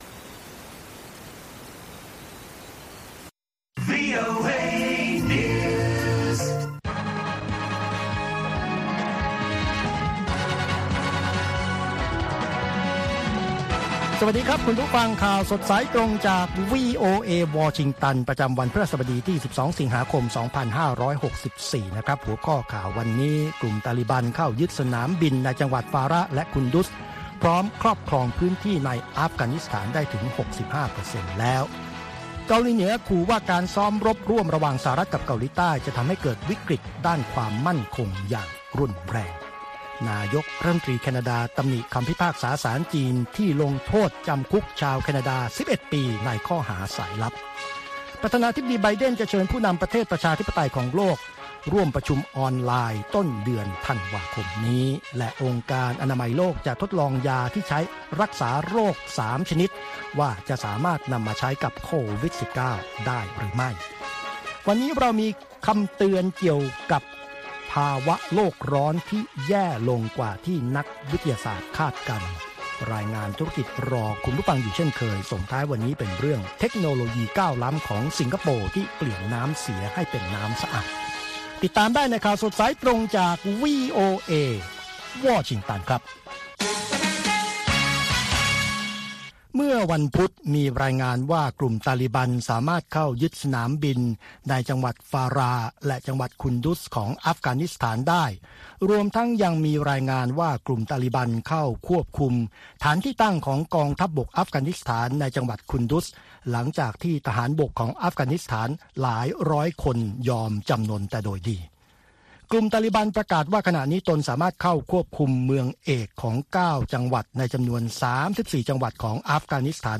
ข่าวสดสายตรงจากวีโอเอ ภาคภาษาไทย 8:30–9:00 น ประจำวันพฤหัสบดีที่ 12 สิงหาคม 2564.